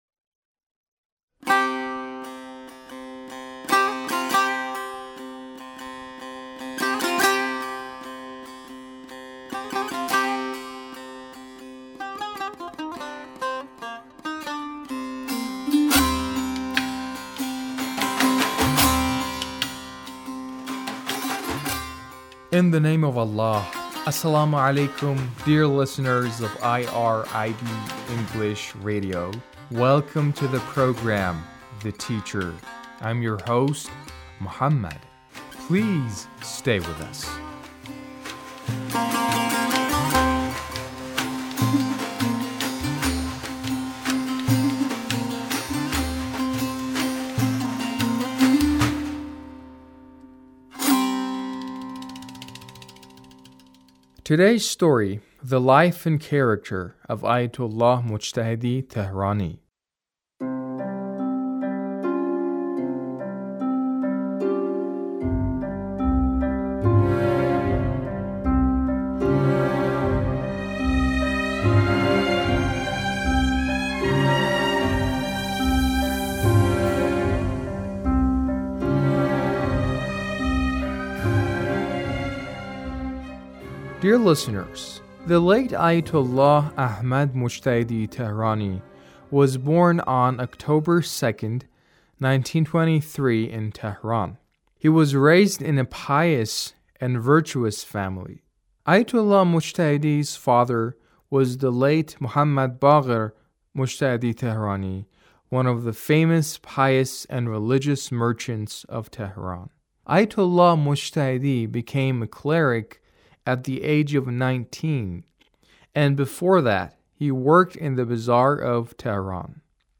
A radio documentary on the life of Ayatullah Mojtahedi Tehrani